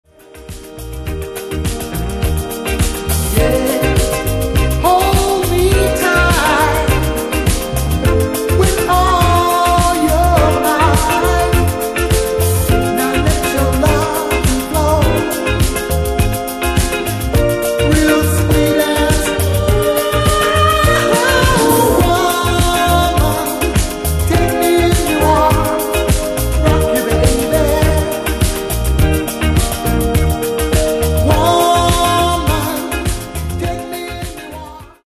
Genere:    Disco | Funk